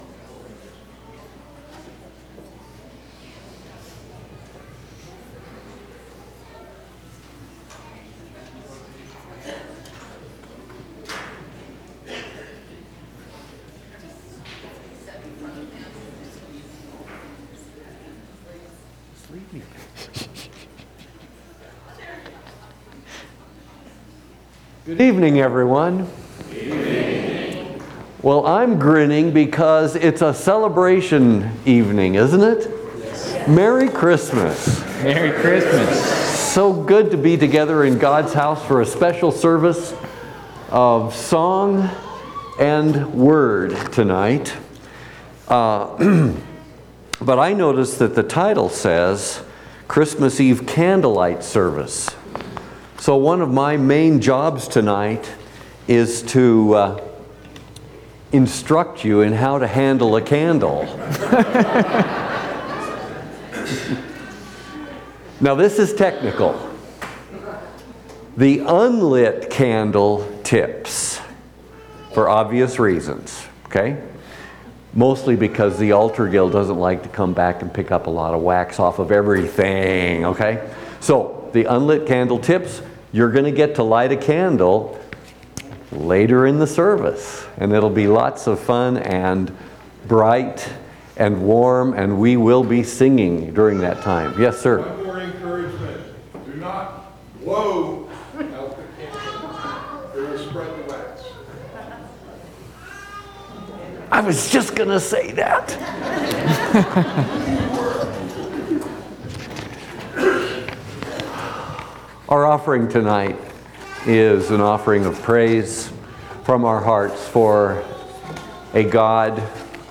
Christmas Eve Service 2025